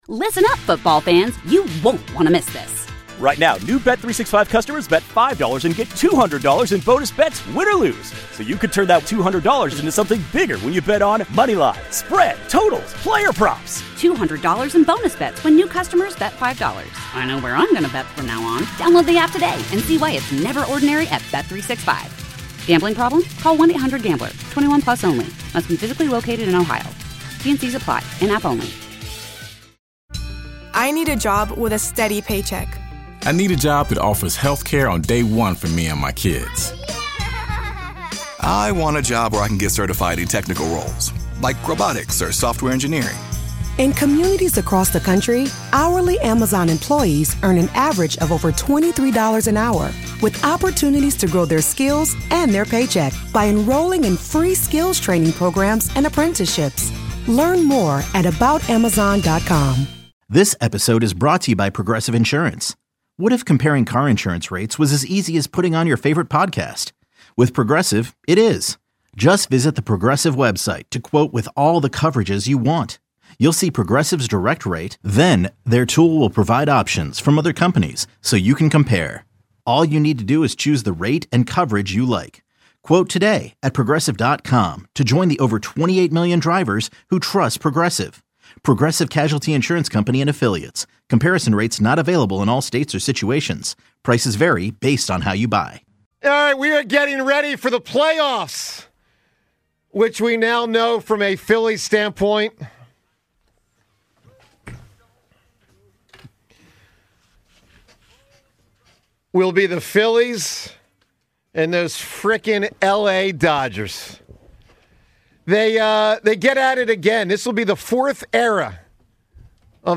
The 94WIP Morning Show delivers everything Philly sports fans crave — passionate takes, smart analysis, and the kind of raw, authentic energy that defines the city.
This is where the voice of the Philly fan is heard loud and clear.
You can catch the 94WIP Morning Show live on SportsRadio 94WIP weekdays from 6–10 a.m. ET.